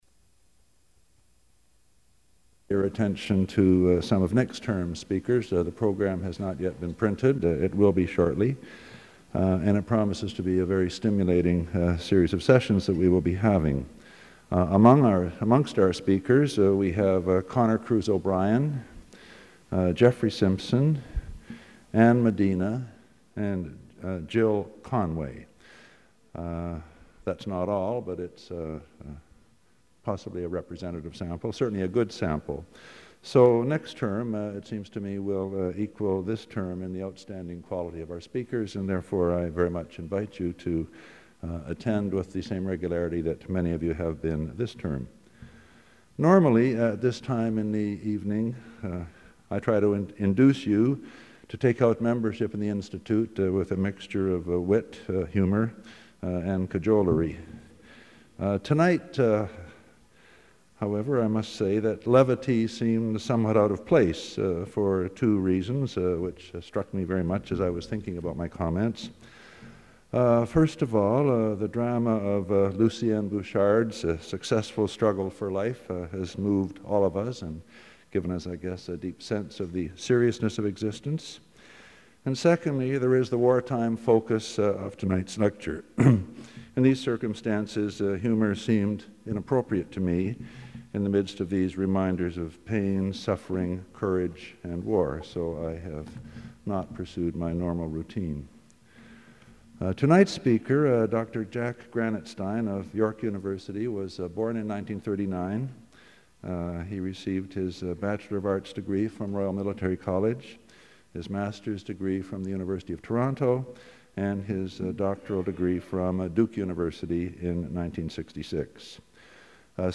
Item consists of a digitized copy of an audio recording of a Vancouver Institute lecture given by Jack Granatstein on December 3, 1994.